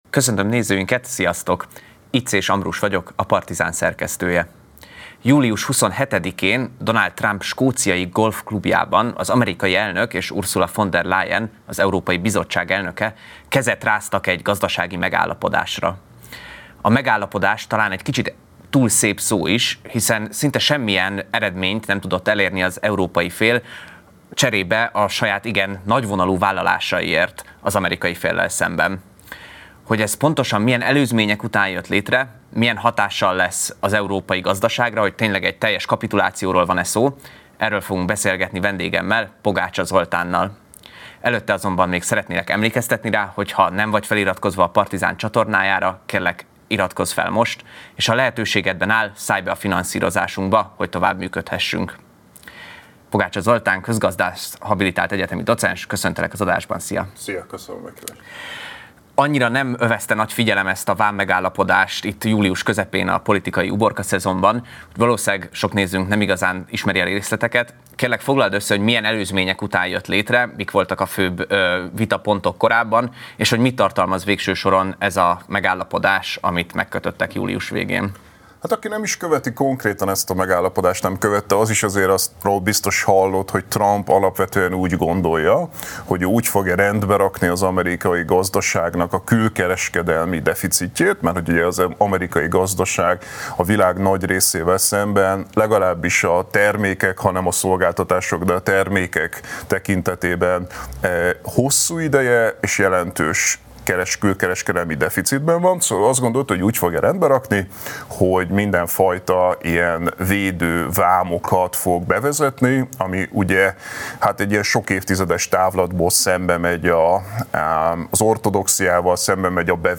Ez a Partizán podcast csatornája: interjúk, elemzések, élő adások, Péntek Reggel.